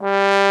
Index of /90_sSampleCDs/Roland L-CD702/VOL-2/BRS_Bs.Trombones/BRS_Bs.Bone Solo